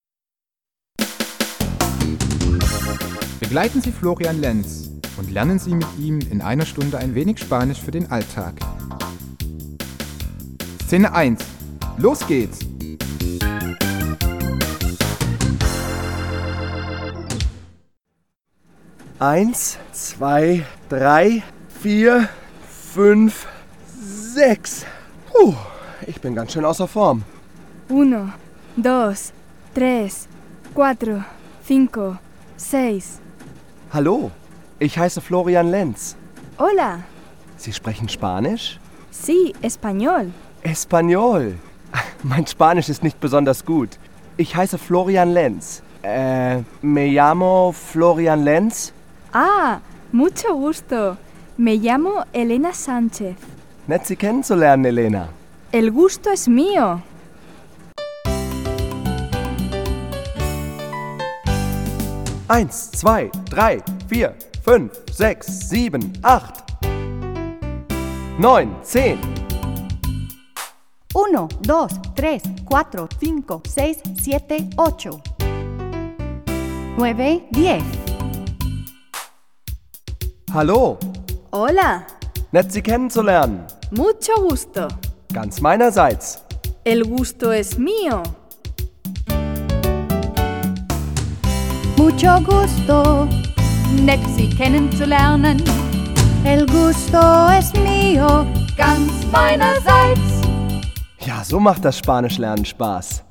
Ein musikalisches Sprachtraining